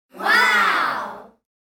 wowGroup1.mp3